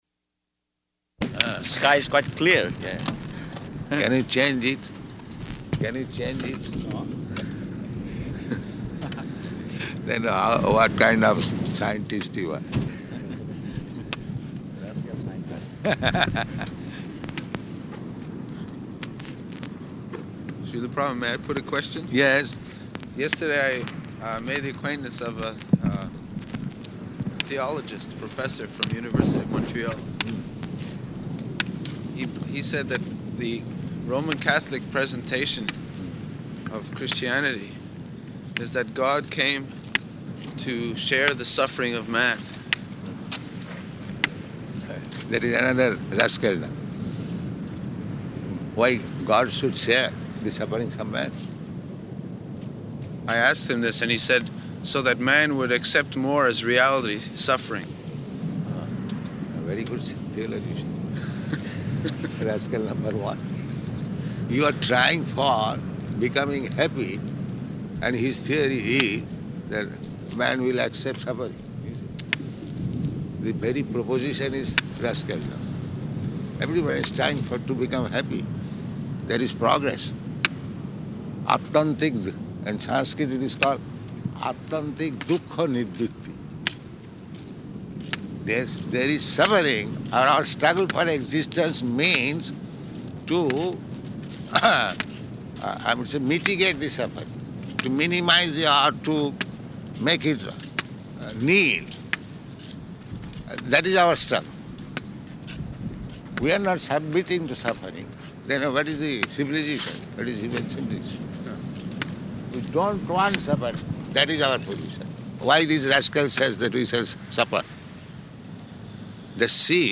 -- Type: Walk Dated: December 15th 1973 Location: Los Angeles Audio file